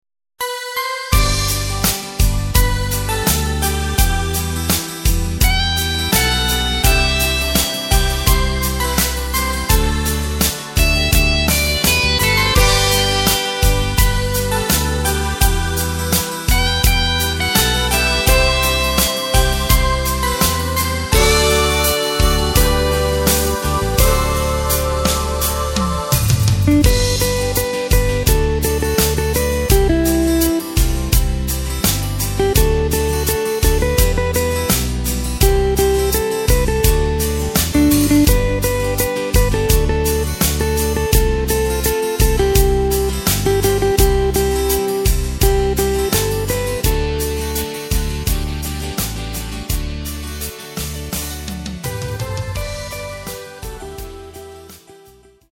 Takt:          4/4
Tempo:         84.00
Tonart:            G
Schöner Schlager!